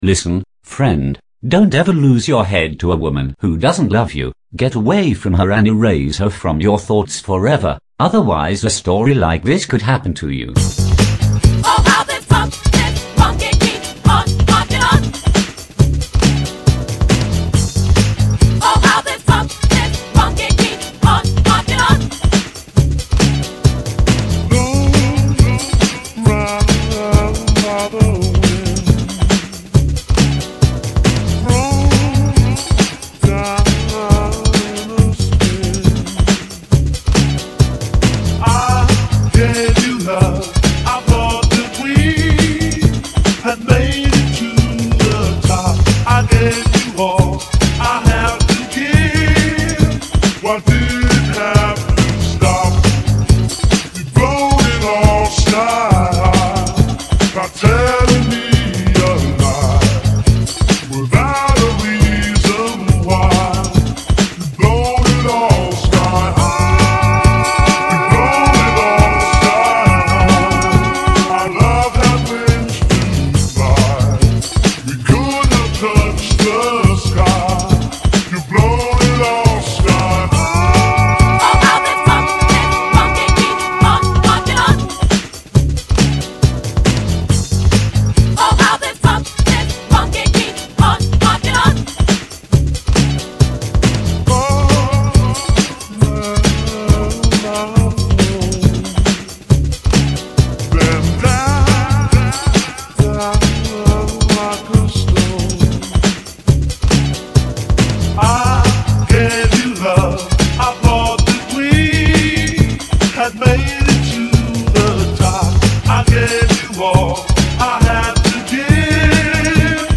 mashup